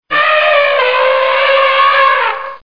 Tiếng voi kêu: